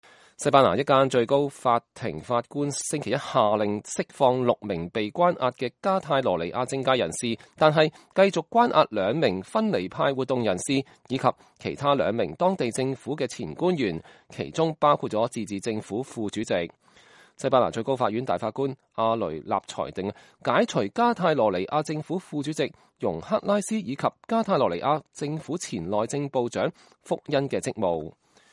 西班牙民眾舉行支持加泰羅尼亞獨立的集會，要求釋放被關押在巴塞羅那的六名政界人士。（2017年12月4日）